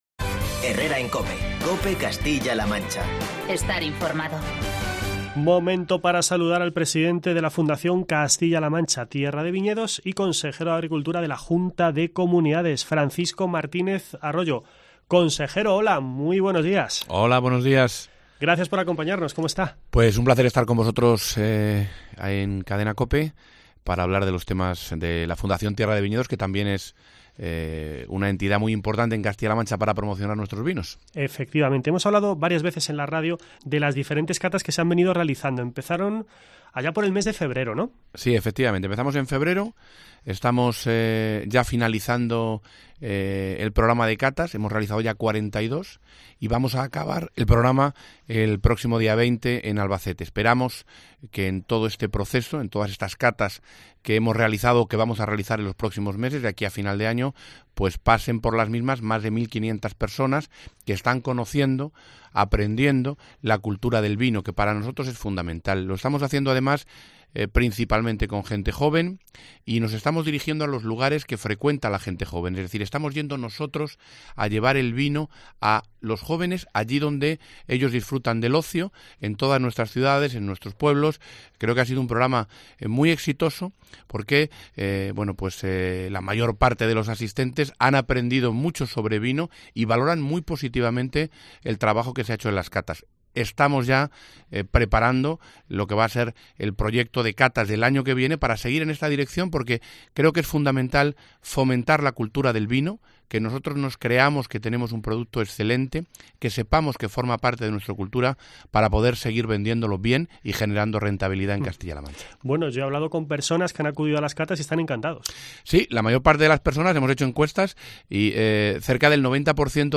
Charlamos con el presidente de la Fundación "Castilla-La Mancha, Tierra de Viñedos" y consejero de Agricultura de la Junta de Comunidades